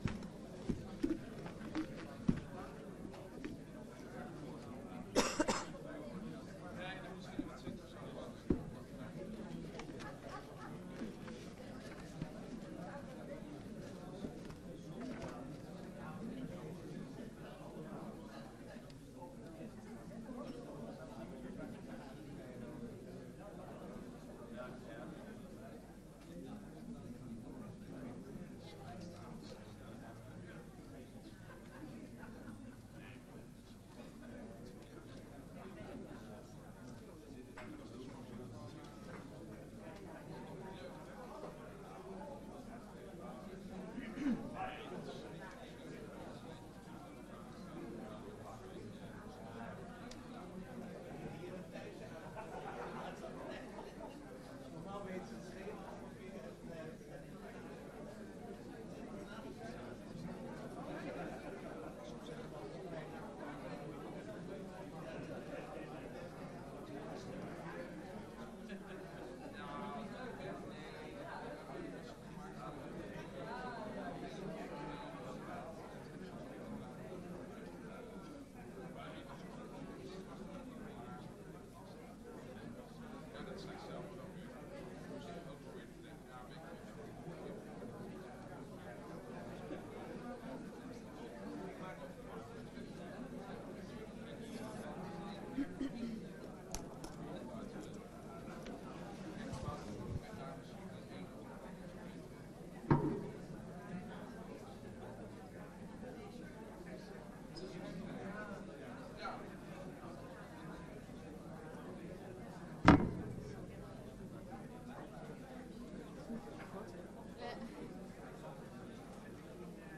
De agendacommissie heeft 27 januari jl. besloten de commissie Ruimte te laten vervallen en in plaats hiervan de technische sessie Valkenburgerlaan, Camplaan en Van Merlenlaan in te plannen.Voor de technische sessie zullen stakeholders en pers worden uitgenodigd en zijn via aanmelding 50-70 inwoners welkom.
Locatie: Raadzaal